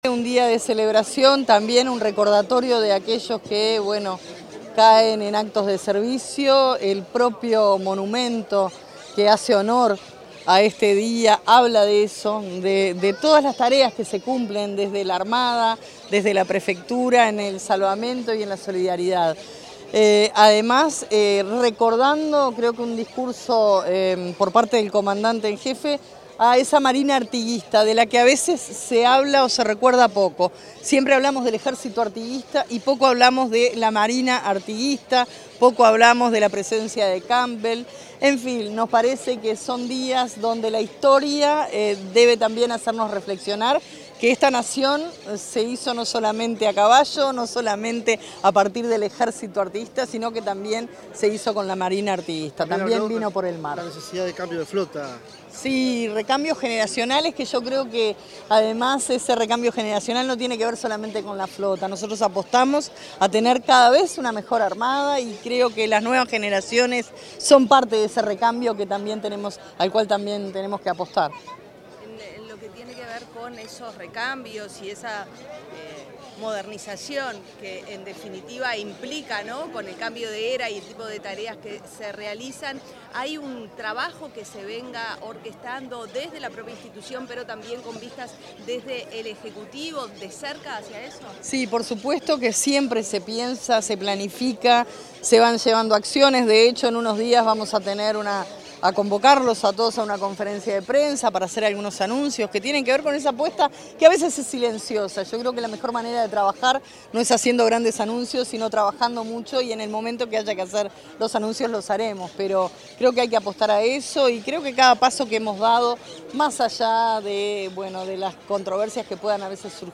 Declaraciones de la ministra de Defensa Nacional, Sandra Lazo
Al finalizar el acto por el Día de la Armada Nacional, la ministra de Defensa, Sandra Lazo, efectuó declaraciones a la prensa sobre la importancia de